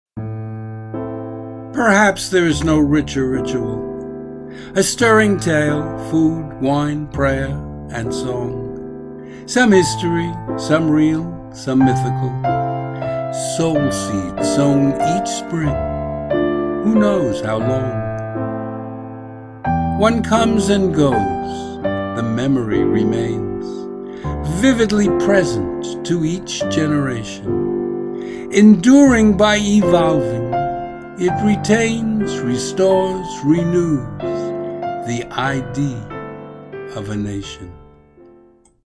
Gymnopedie No.3.